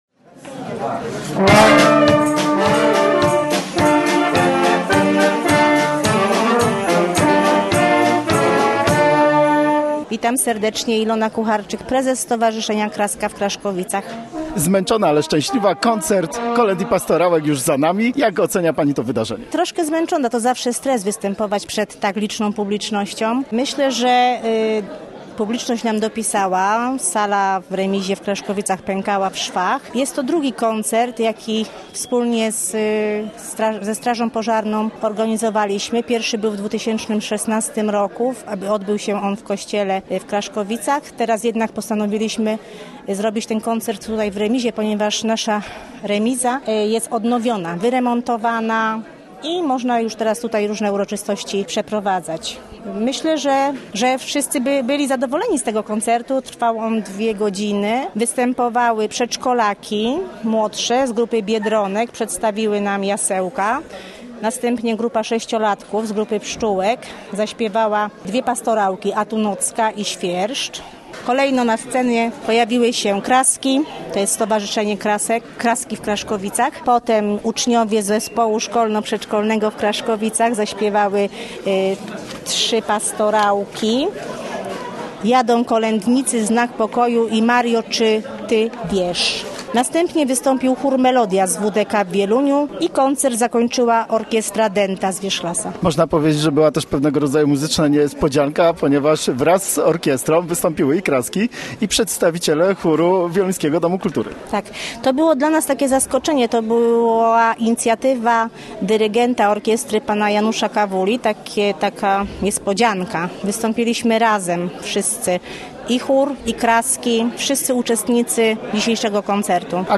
W Kraszkowicach zaśpiewali kolędy i pastorałki (zdjęcia)
Stowarzyszenie „Kraska” i Ochotnicza Straż Pożarna z Kraszkowic zorganizowali w niedzielę Koncert Kolęd i Pastorałek. Koncert rozpoczęły dzieci z Zespołu Szkolno-Przedszkolnego w Kraszkowicach.
Koncert trwał dwie godziny, przedszkolaki przygotowały jasełka, starsze dzieciaki zaśpiewały pastorałki. Wystąpiły także członkinie Stowarzyszenia „Kraski”, Chór „Melodia” z Wieluńskiego Domu Kultury a występy zakończyła Orkiestra Dęta z Wierzchlasa.